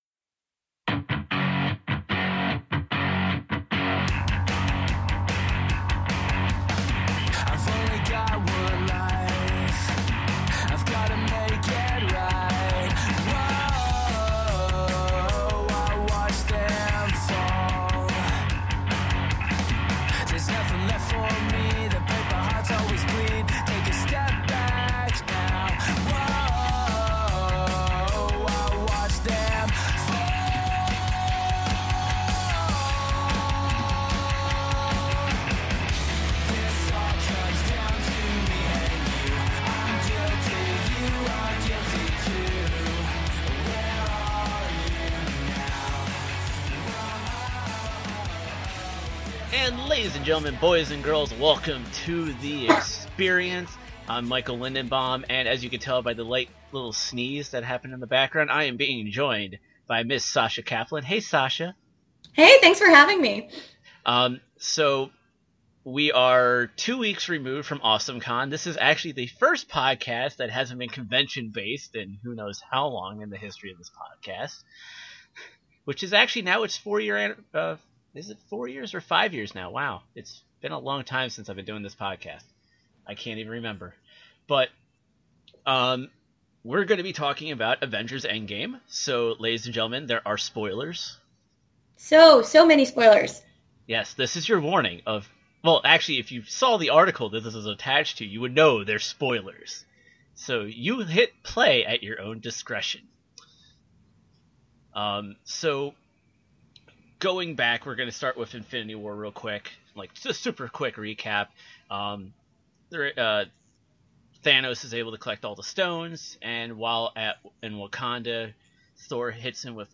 Also this episode contains minor foul language and SPOILERS about the film.
xperience-avengers-endgame-review.mp3